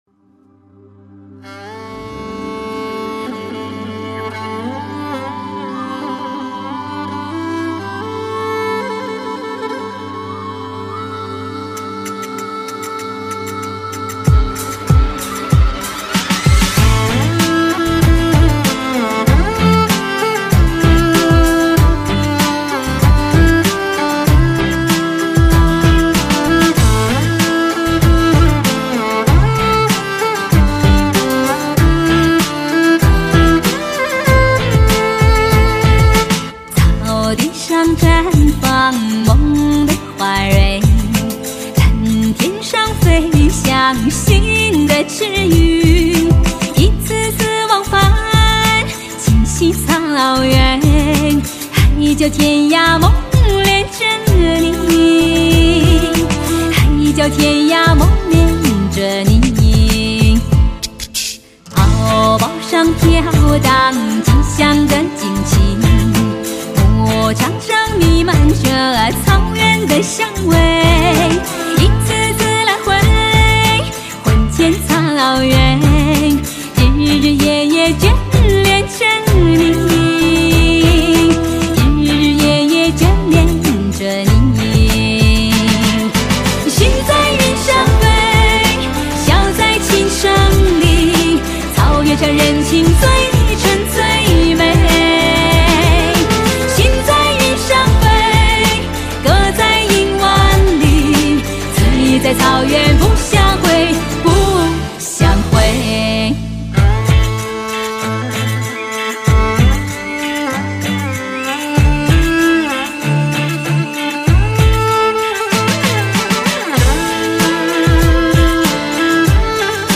类型: 天籁人声
最温柔的情歌，最奔放的情怀，听着她的天籁之音再次席卷而来……在她的歌声里，心可以零污染。